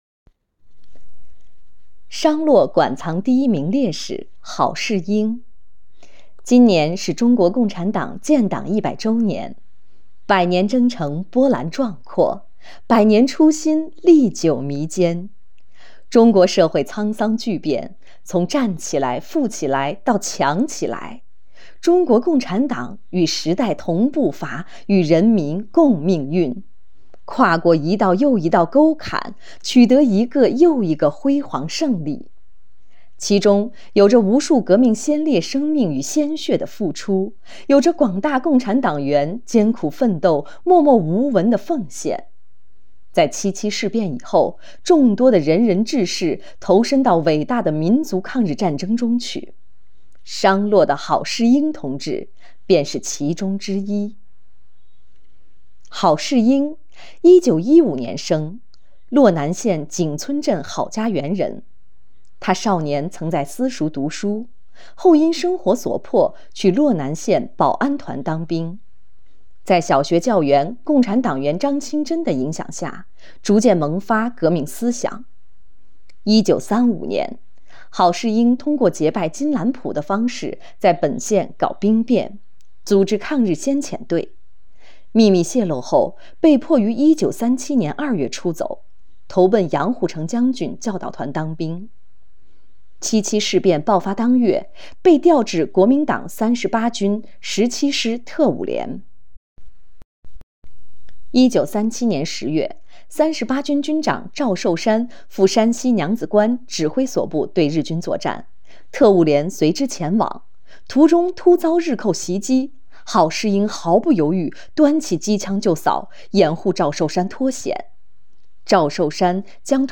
【红色档案诵读展播】商洛市档案馆馆藏第一名烈士郝世英